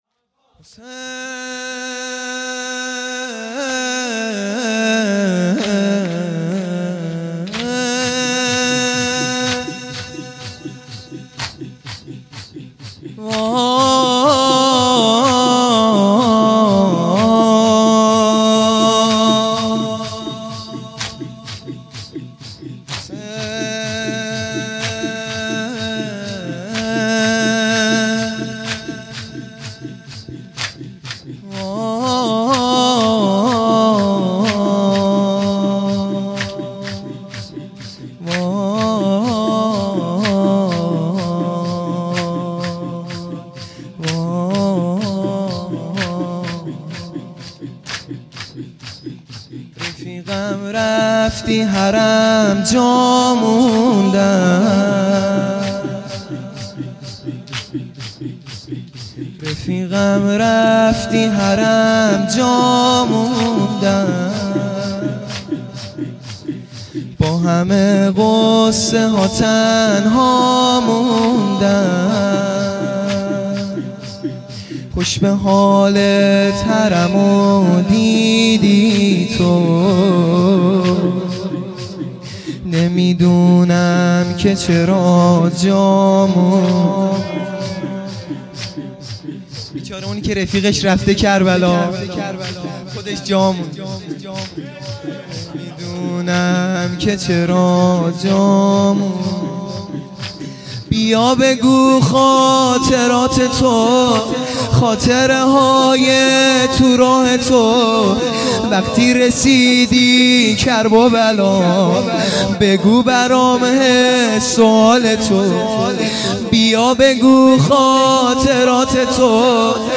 شور
دهه آخر صفر | شب دوم